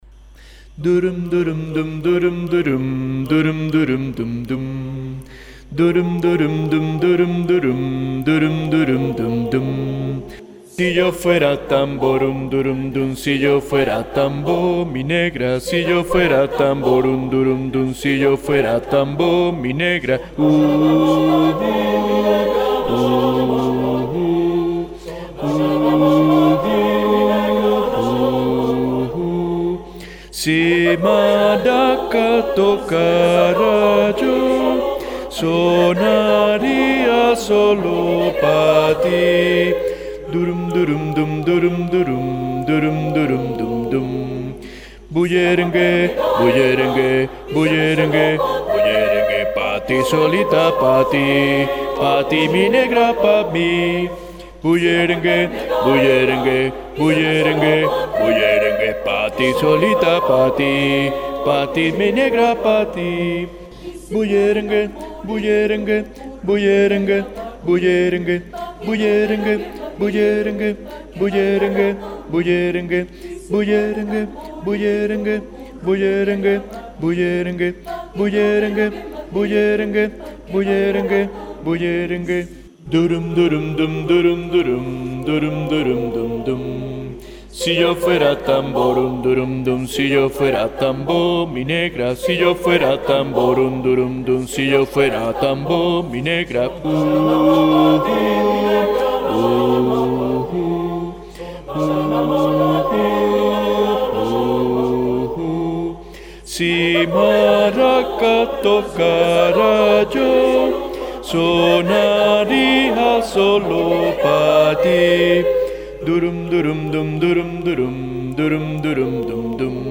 Música Popular- Bajos
Bullerengue
Bullerengue-bajos.mp3